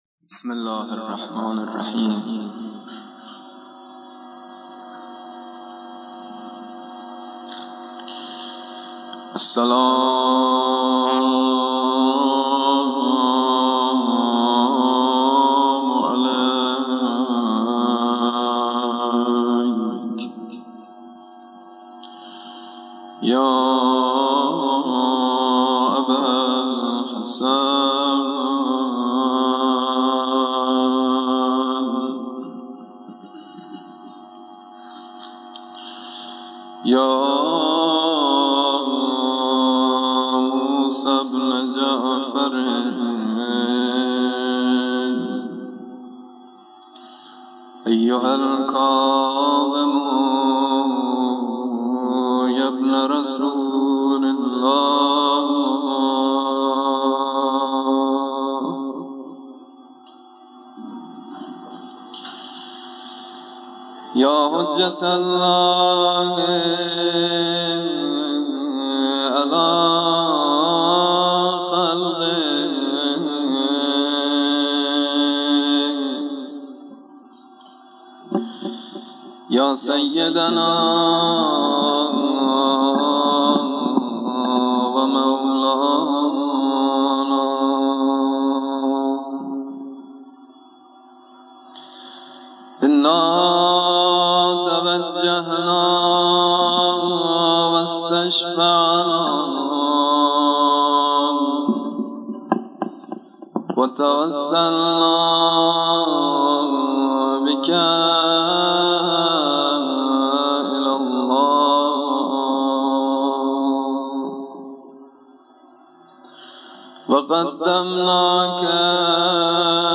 روضه شهادت امام موسی کاظم علیه السلام 25 رجب سال 1421هـ.ق - مدائح و مراثی - مداح شماره 1 | مکتب وحی
روضه شهادت امام موسی کاظم علیه السلام 25 رجب سال 1421هـ.ق